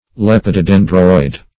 Search Result for " lepidodendroid" : The Collaborative International Dictionary of English v.0.48: Lepidodendroid \Lep`i*do*den"droid\ (-droid), a. (Paleon.)